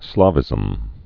(slävĭzəm) or Slav·i·cism (slävĭ-sĭzəm)